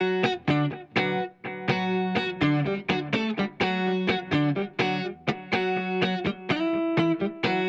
32 Guitar PT1.wav